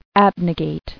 [ab·ne·gate]